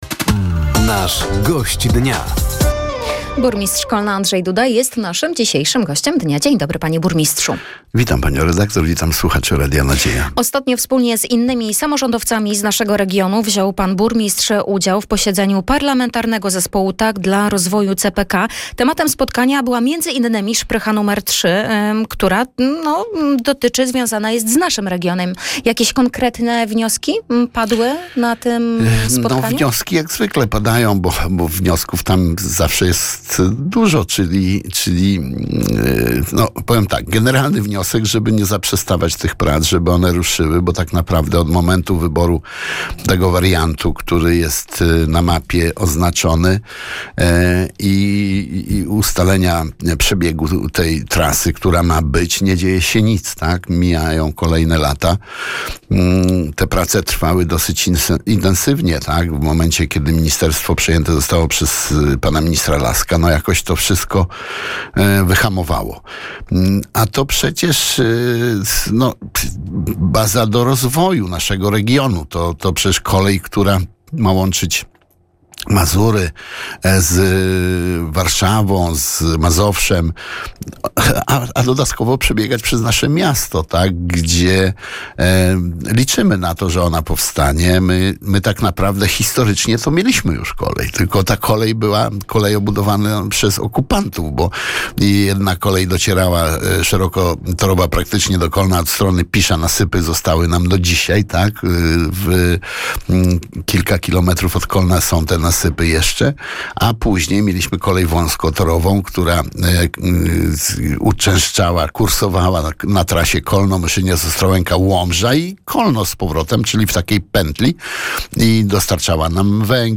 Walka samorządowców o szprychę nr 3, budowa bloku komunalnego, a także pierwszy w historii miasta Budżet Obywatelski – to główne tematy rozmowy z wtorkowym (21.04) Gościem Dnia Radia Nadzieja, którym był burmistrz Kolna Andrzej Duda.